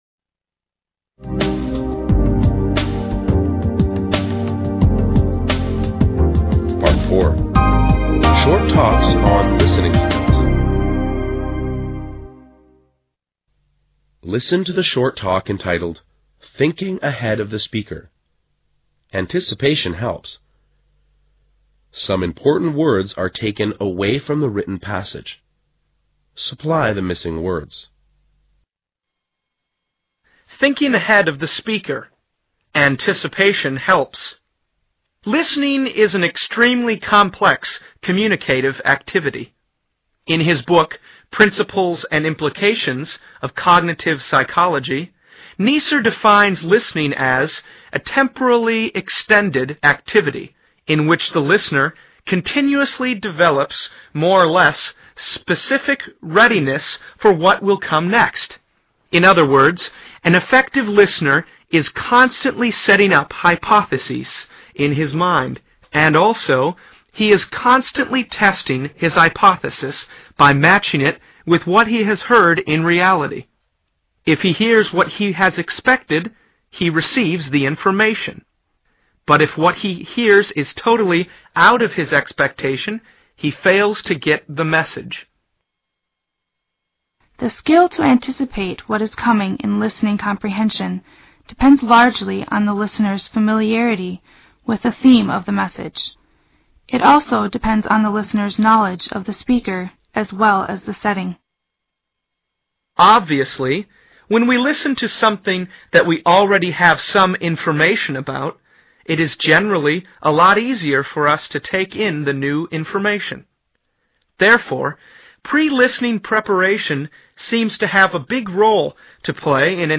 Part 4. Short talks on listening skills.